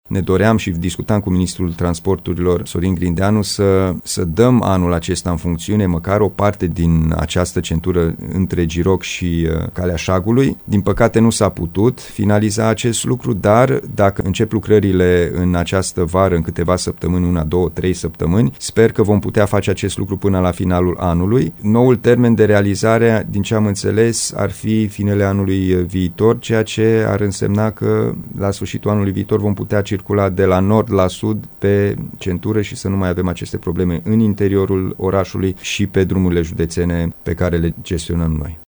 Un prim tronson din centura Sud a Timișoarei, între Giroc și Calea Șagului, ar putea fi dat în folosință până la sfârșitul acestui an. Anunțul a fost făcut la Radio Timișoara de președintele Consiliului Județean Timiș.